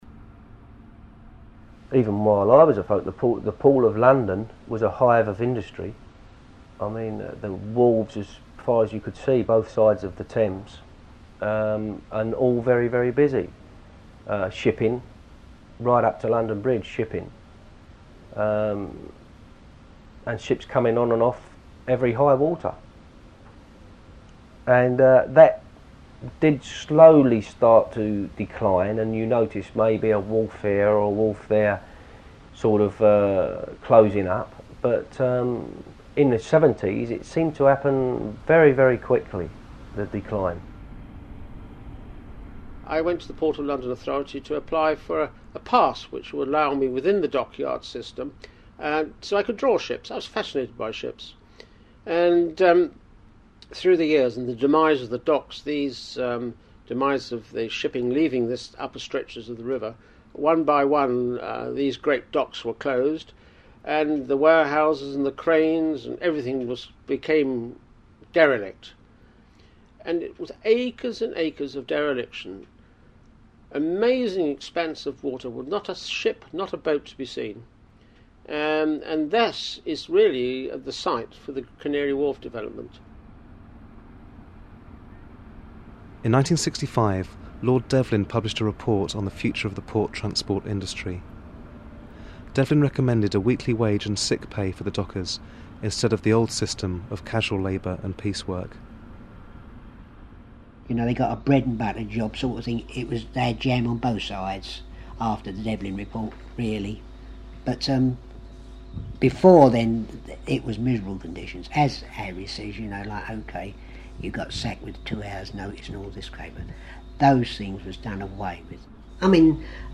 Memoryscape logo Voices from the hidden history of the Thames
I think that hearing the analysis of people who lived the history and lost their jobs gives the different theories a certain power; I wanted to see if memories could help explain quite complex economic history and geography.